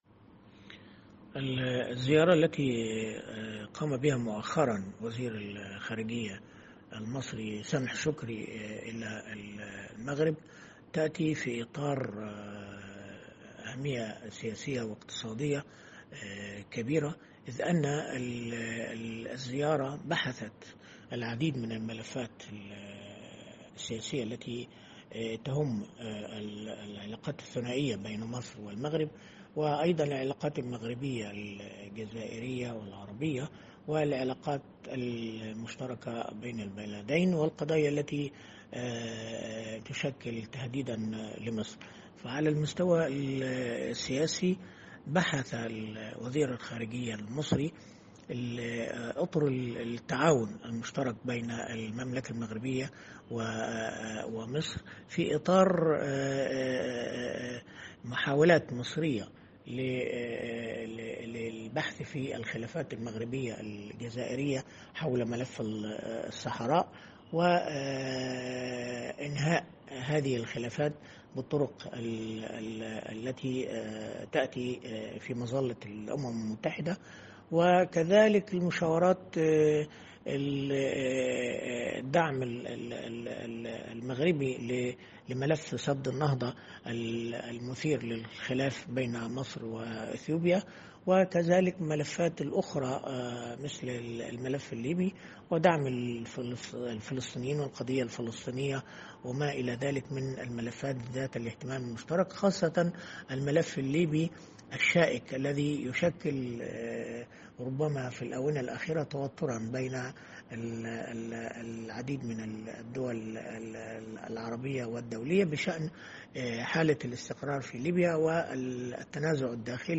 الكاتب الصحفي والمحلل السياسي